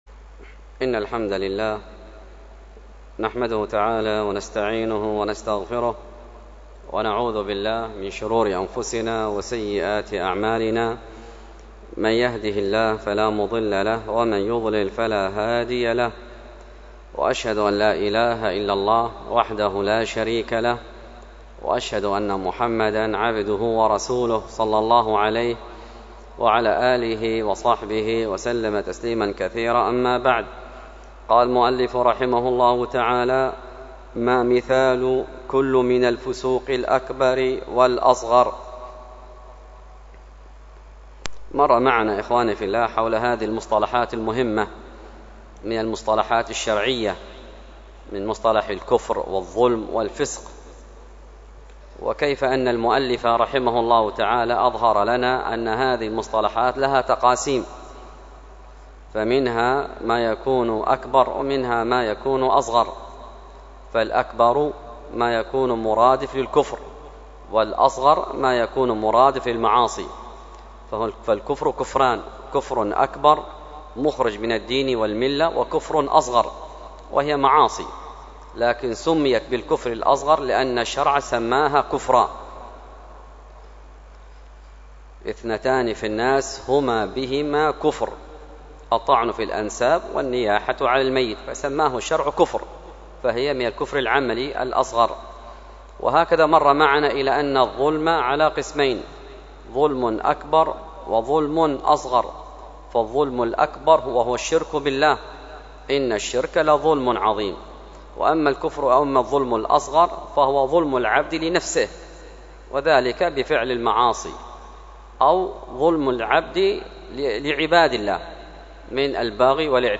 الدرس في شرح أصول في التفسير للعثيمين 23، الدرس الثالث والعشرون : من : ( الواجب على المسلم في تفسير القرآن ... أليس في جهنم مثوى للمتكبرين ).